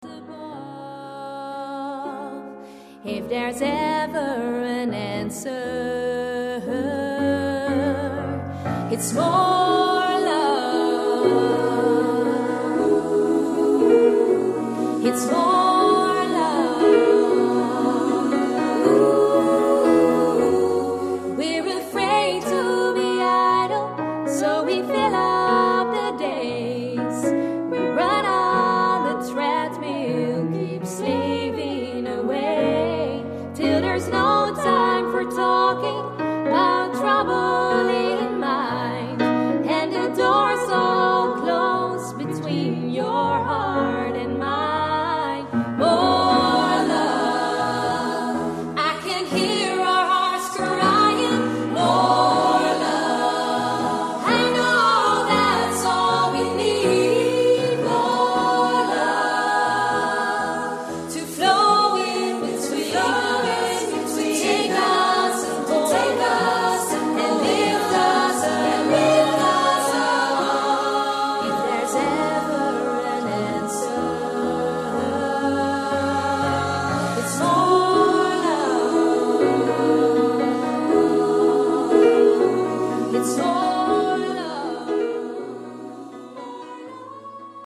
Arrangementen voor koor.